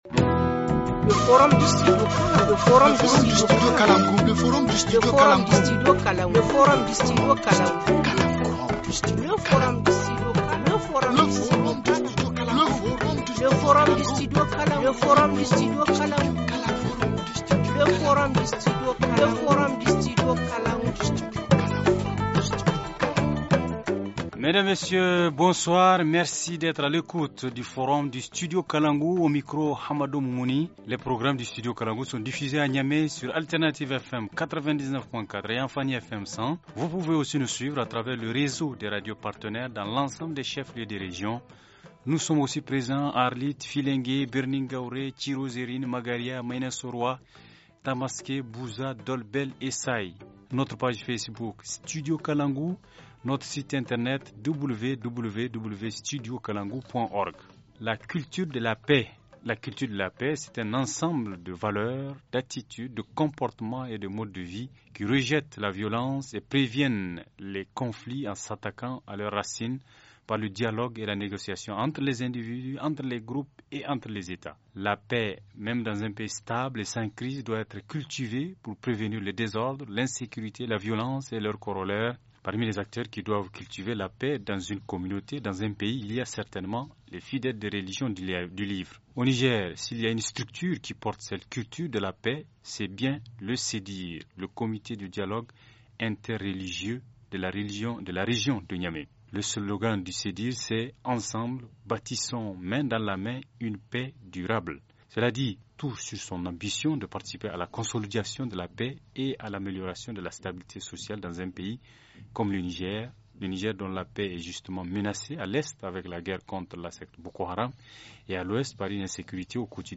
Forum du 29/06/2017 - La culture de la Paix - Studio Kalangou - Au rythme du Niger